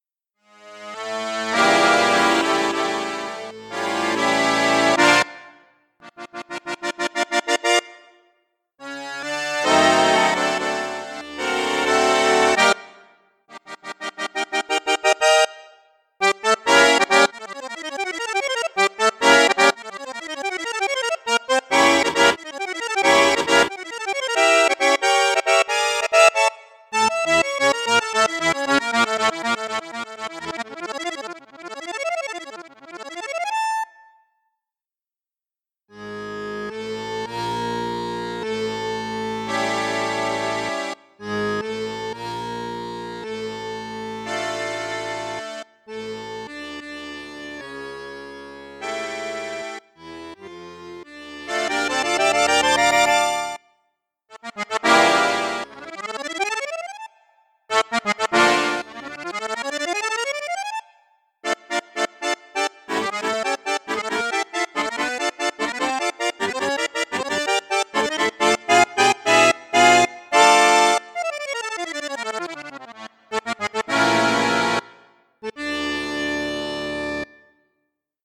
Accordéon chromatique d'occasion, 4 voix, double boite de résonance, 120 basses, 5 voix MG.
Les yeux noirs registre basson + 2 flutes + piccolo
demo-registre-basson-flute-juste-flute-haute-piccolo-les-yeux-noirs.mp3